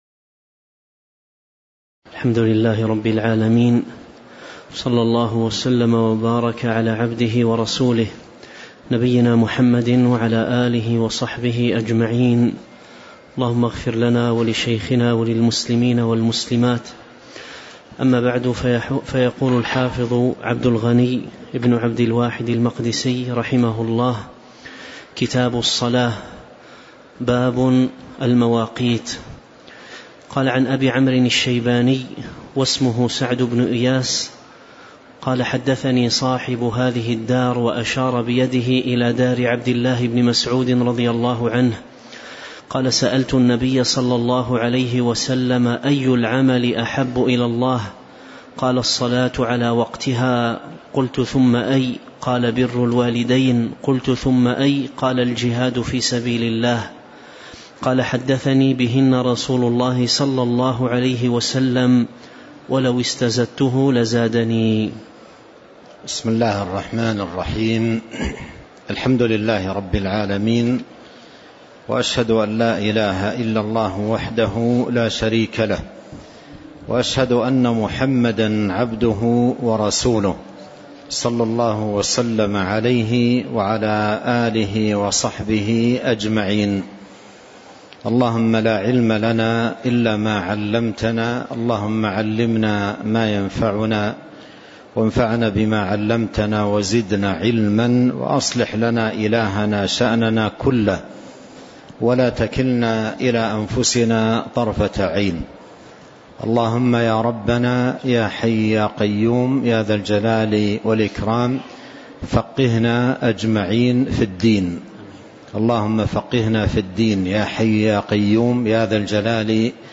تاريخ النشر ٢٢ ربيع الأول ١٤٤٤ هـ المكان: المسجد النبوي الشيخ